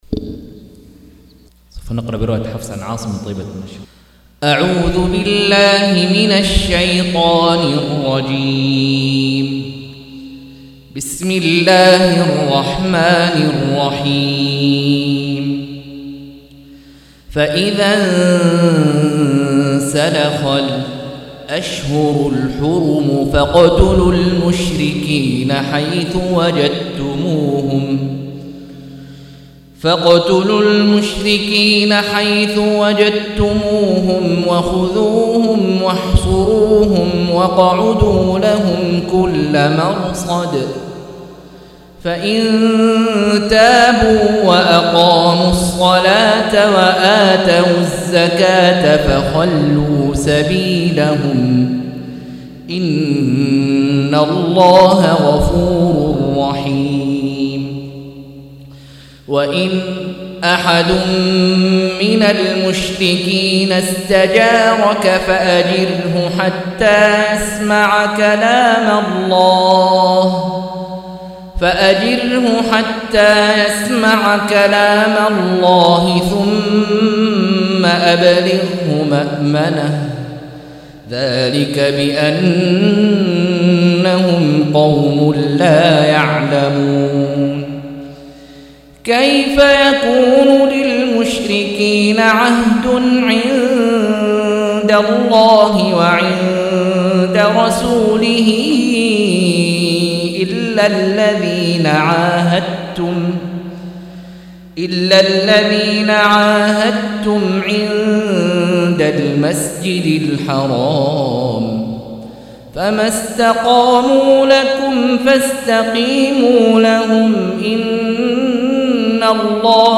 178- عمدة التفسير عن الحافظ ابن كثير رحمه الله للعلامة أحمد شاكر رحمه الله – قراءة وتعليق –